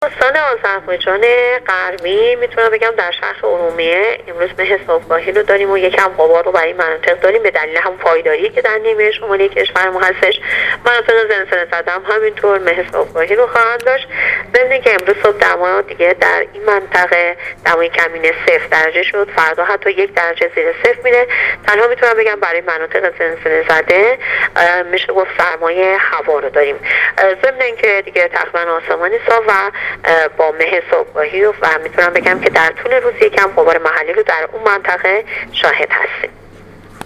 گفت‌وگو با رادیو اینترنتی وزارت راه و شهرسازی